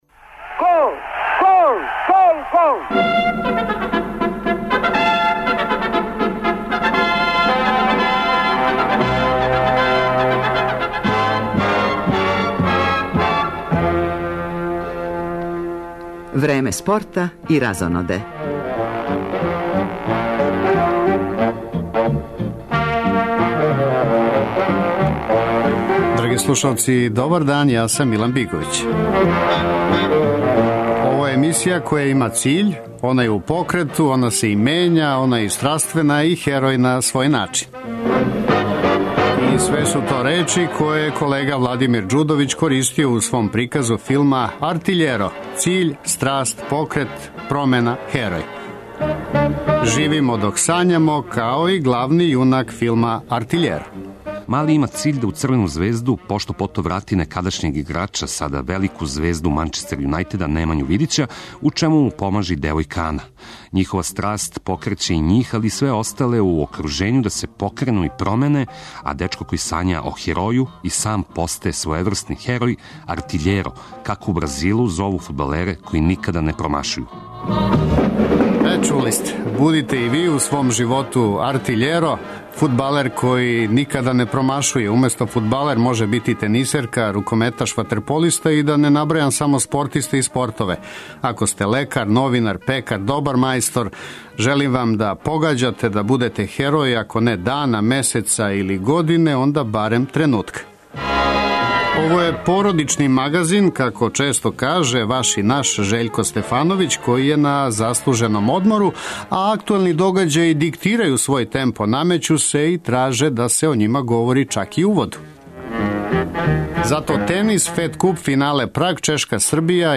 Пратимо уживо наступ женске тениске Фед куп репрезентације која игра у суботу и недељу у Прагу финале овог такмичења.